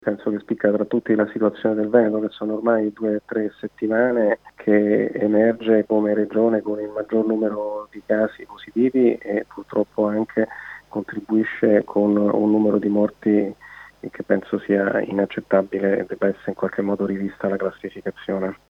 Della situazione epidemiologica in Veneto e della campagna vaccinale contro il Covid, abbiamo parlato con il microbiologo Andrea Crisanti: